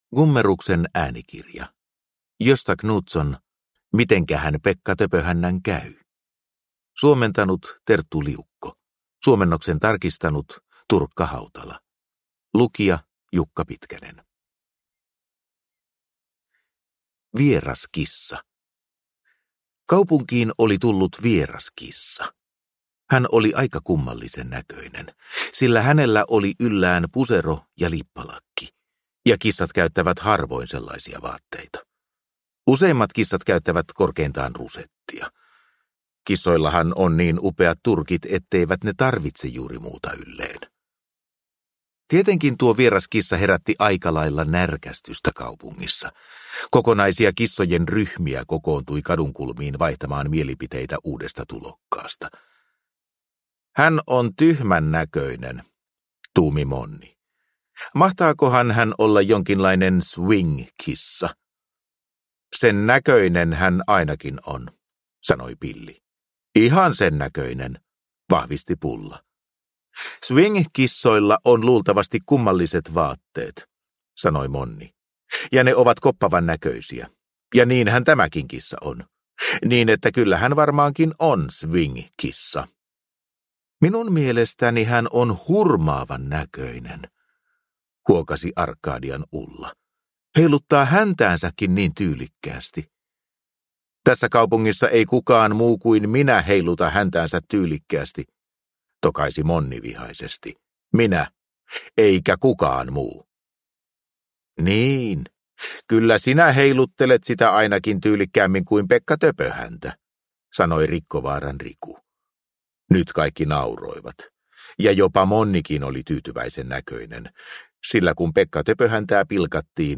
Mitenkähän Pekka Töpöhännän käy? – Ljudbok – Laddas ner